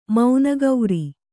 ♪ mauna gauri